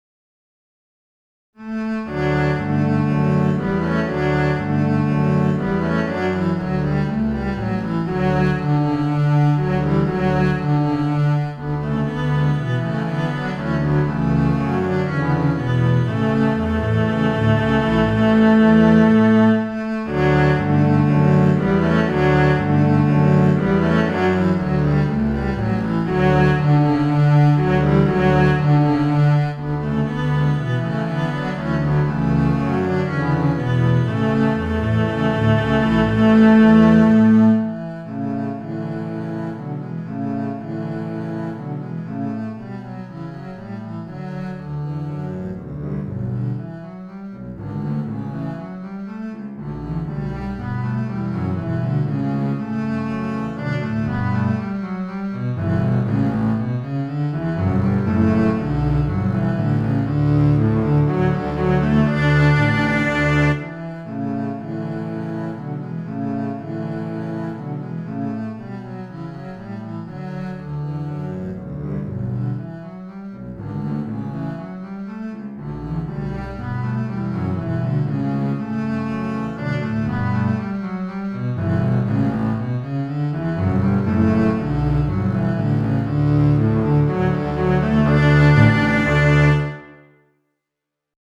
Voicing: 4 Bass